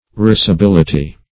Risibility \Ris`i*bil"i*ty\, n. [CF. F. risibilit['e].]